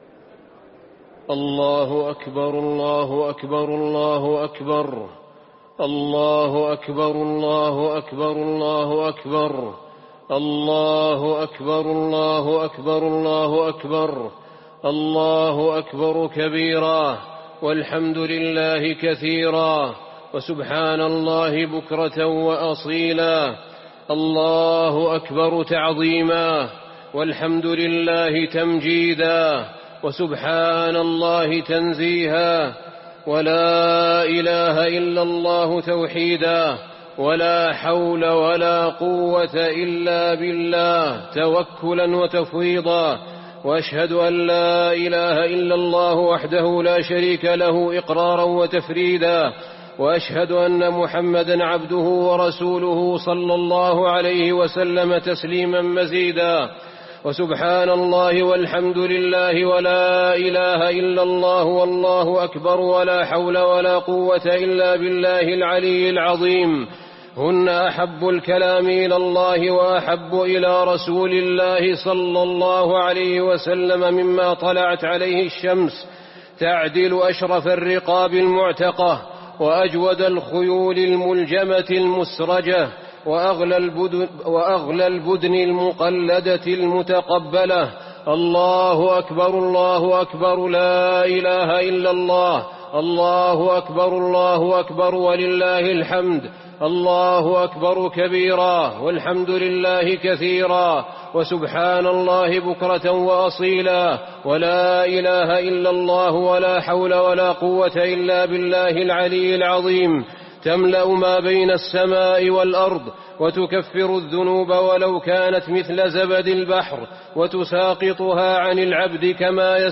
خطبة عيد الأضحى - المدينة - الشيخ أحمد بن طالب
تاريخ النشر ١٠ ذو الحجة ١٤٤١ هـ المكان: المسجد النبوي الشيخ: فضيلة الشيخ أحمد بن طالب بن حميد فضيلة الشيخ أحمد بن طالب بن حميد خطبة عيد الأضحى - المدينة - الشيخ أحمد بن طالب